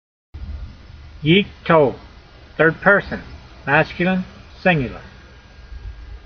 My reading (voice) in modern Israeli style is only good enough to get you started.
yeek-tol